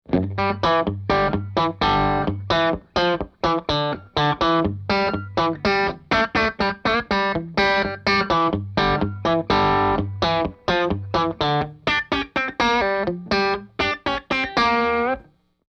Country Rhythm
country-rhythm.mp3